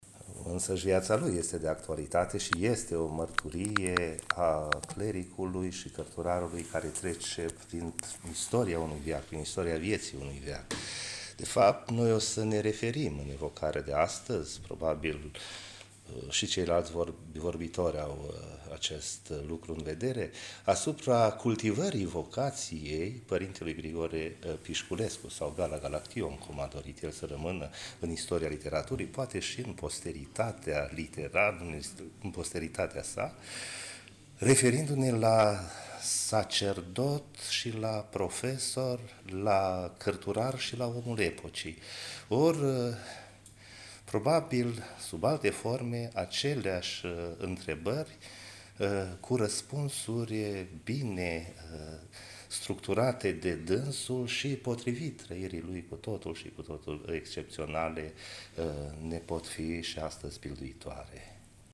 Universitatea „Alexandru Ioan Cuza” din Iași (UAIC) a organizat miercuri, 16 aprilie 2025, de la ora 12.00, în Mansarda Muzeului UAIC, conferința „Gala Galaction – profesor la Universitatea din Iași (1926-1940)”.